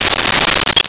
Coins Thrown 02
Coins Thrown 02.wav